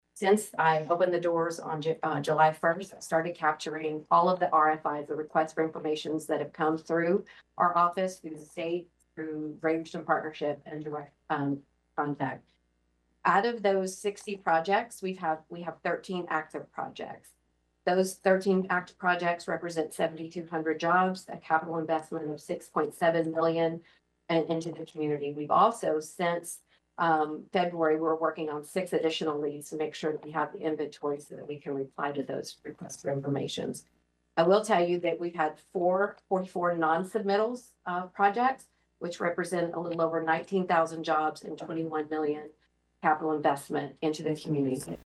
PRESENTATION TO COMMISSIONERS COURT